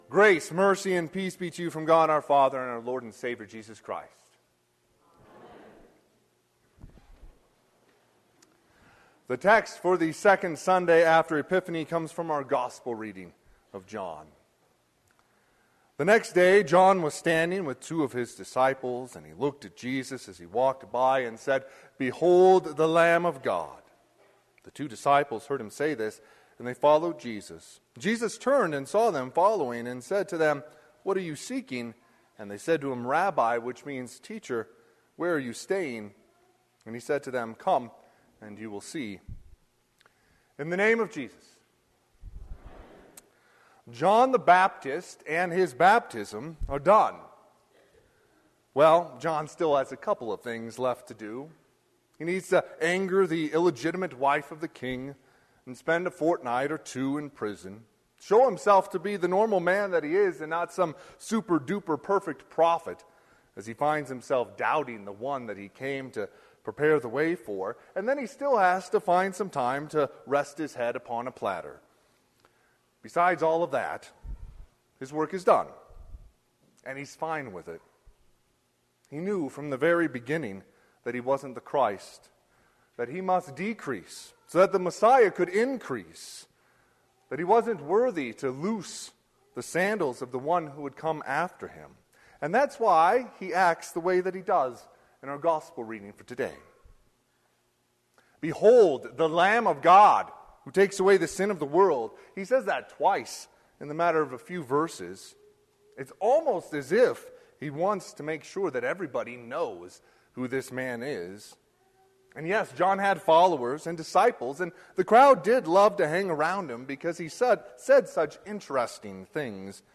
Sermon - 1/15/2023 - Wheat Ridge Lutheran Church, Wheat Ridge, Colorado